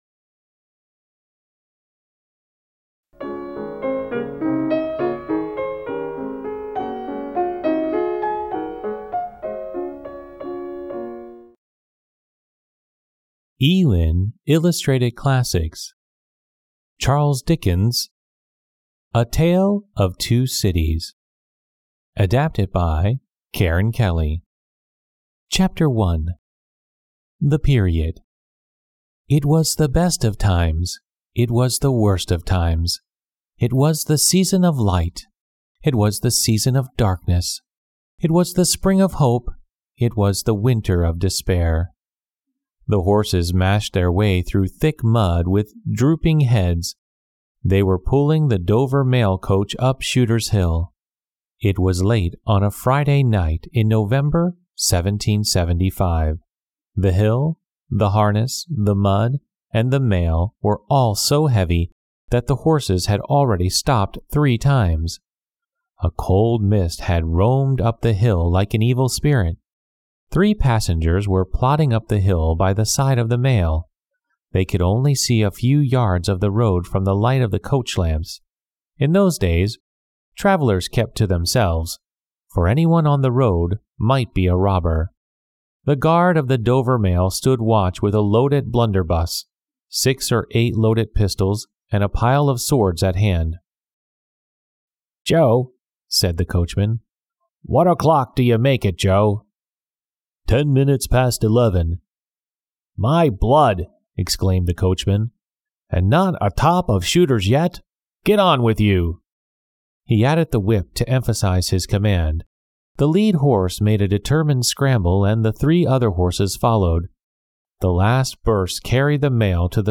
丛书甄选优质中文译本，配以导读、作家作品简介和插图，并聘请资深高考听力卷主播朗读英语有声书。